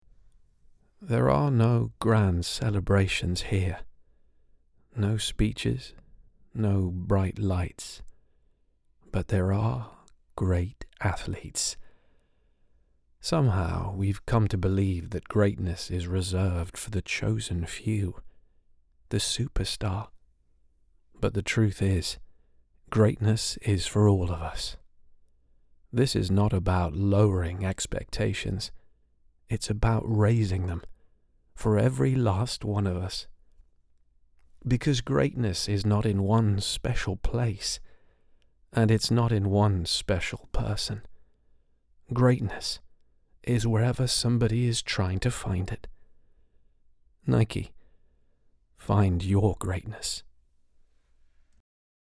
Canadian
Mid-Atlantic
Male
Characterful
Cool
Storytelling
NIKE COMMERCIAL